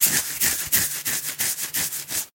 чешет свою подмышку